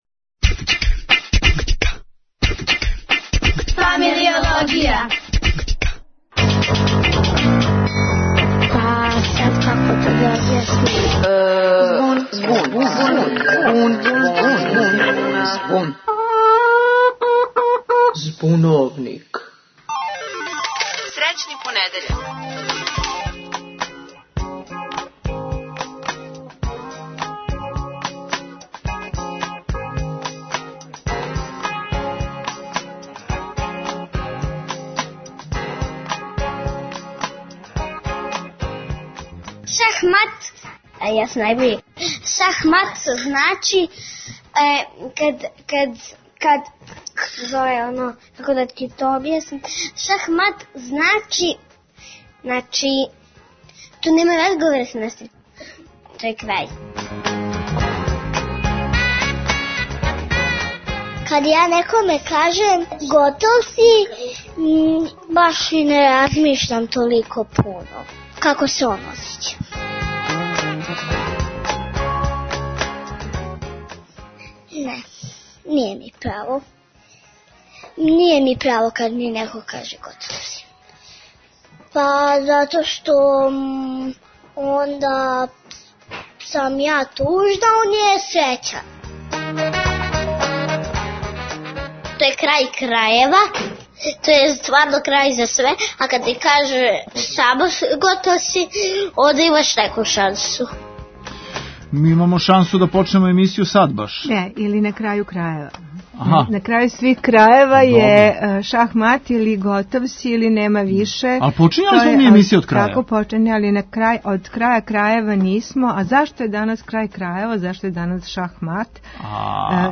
Деца говоре о ћорсокацима и извлачењу из њих;